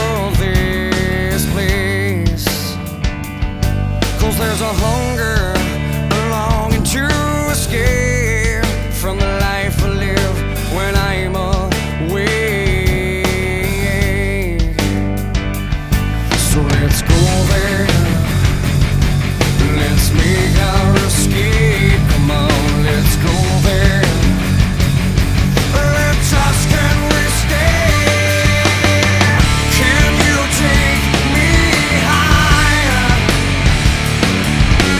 Жанр: Рок / Пост-хардкор / Хард-рок